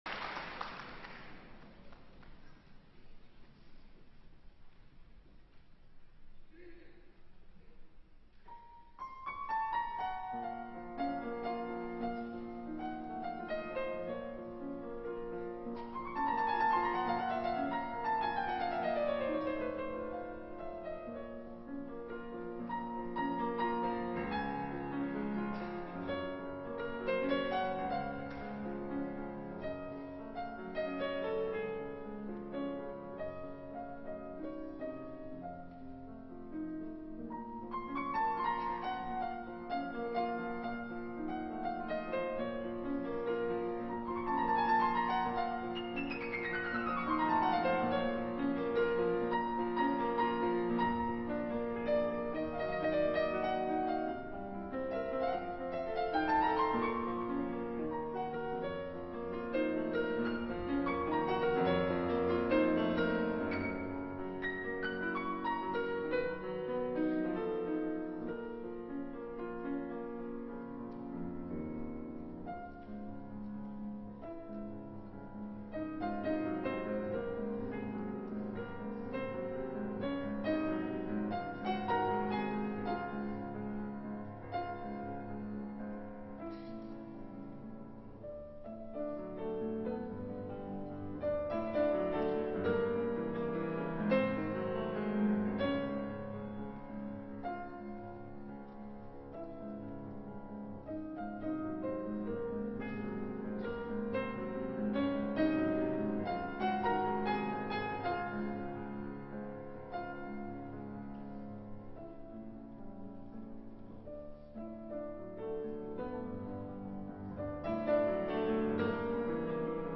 This piece is in ternary form and has a slow tempo. The use of ornaments in the right hands and the soft arpeggio of the left hands described the beautiful night of nature. The key changed to D-flat major in the middle section and the pure and lyrical melody made the mood more dreamy.
Finally, there are three chords like the church bells in the distance, which makes people feel quiet and comforted.
Yundi_Li_Plays_Chopin_Nocturne.mp3